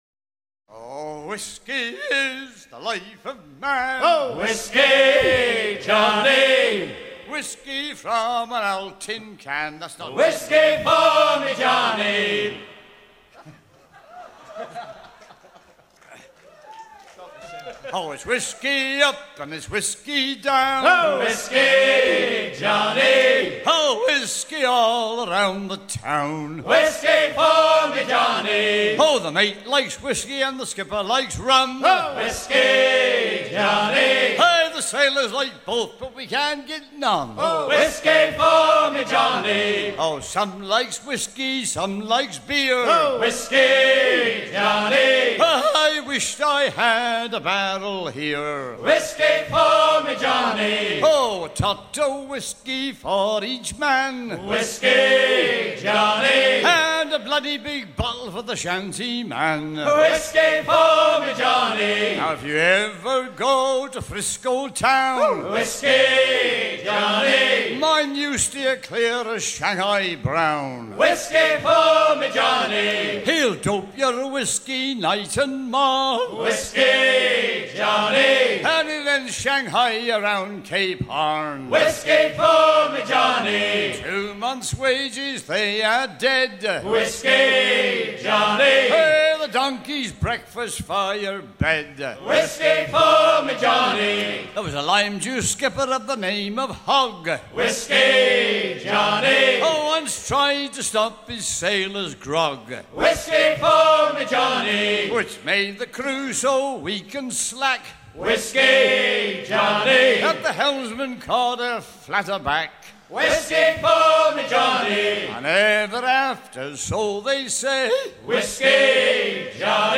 ce shanty était généralement utilisé pour hisser les perroquets ou les petits huniers sur un trois-mâts carré
Pièce musicale éditée